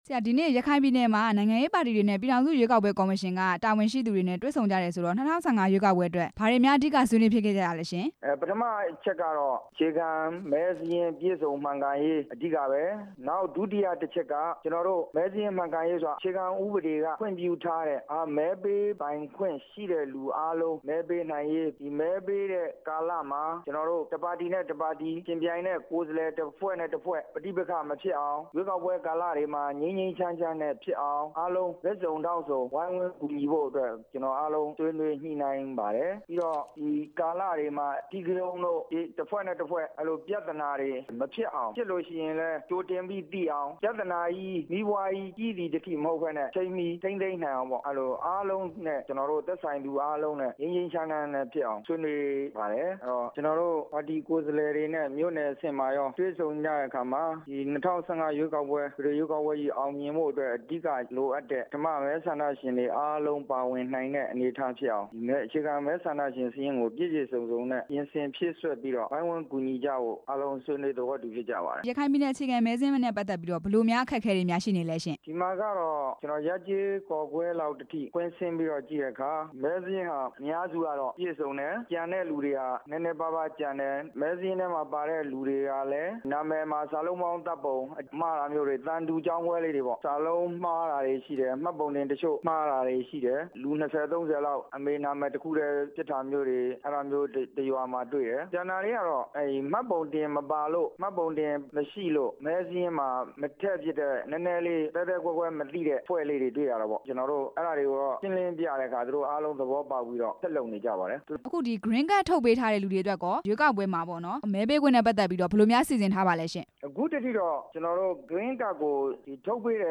ဒေါက်တာ မောင်မောင်ကြည်ကို မေးမြန်းချက်